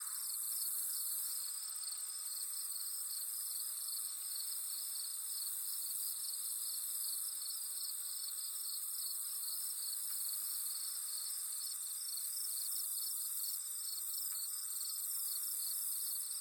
night.wav